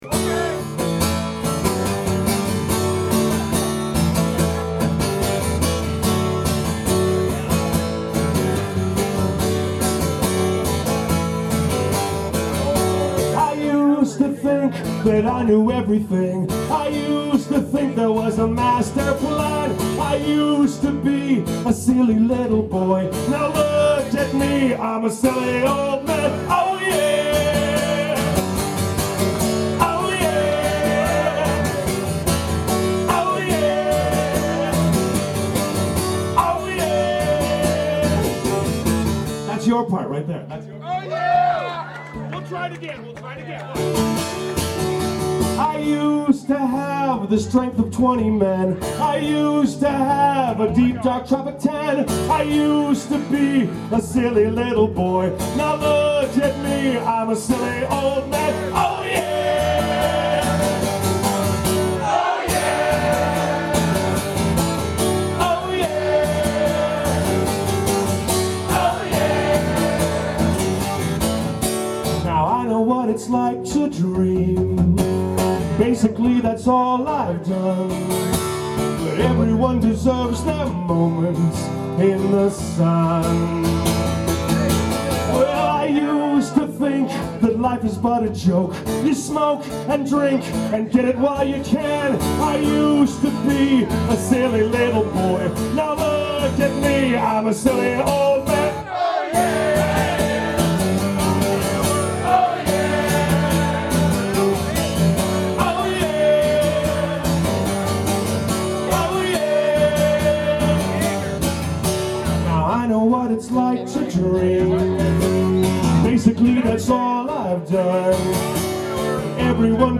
Recorded at Shank Hall 3/16/02